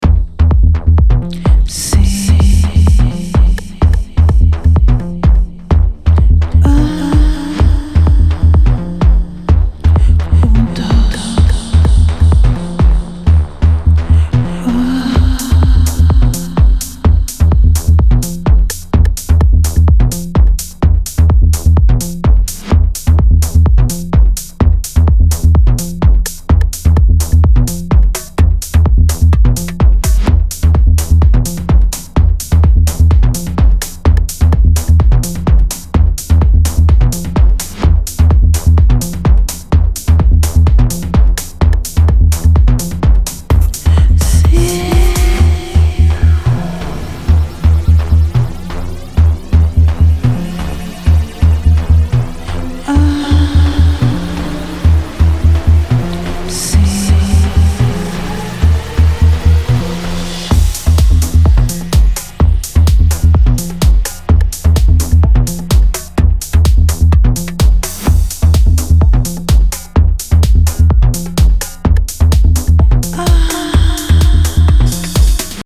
• Afro House